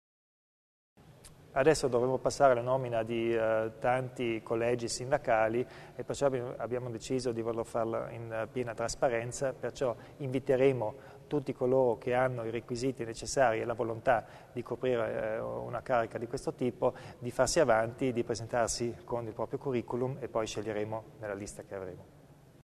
Il Presidente Kompatscher illustra le nuove iniziative in tema di trasparenza